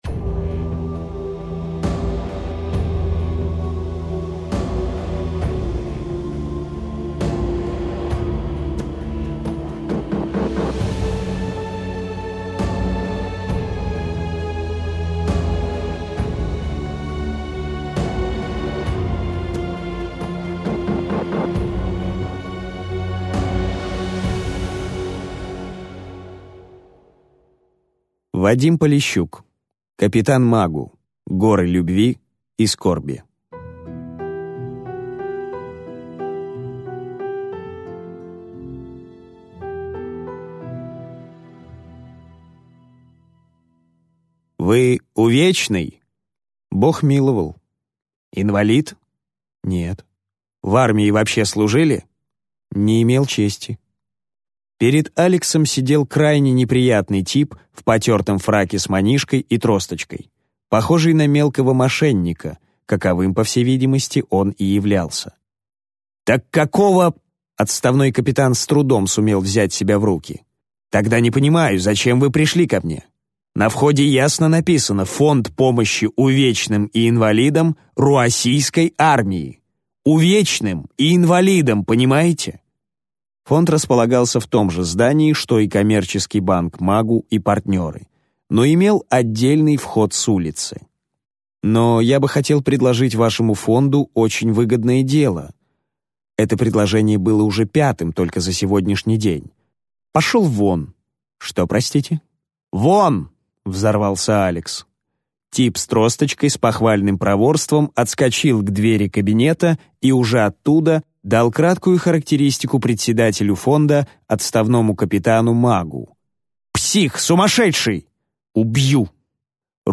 Аудиокнига Капитан Магу. Горы любви и скорби | Библиотека аудиокниг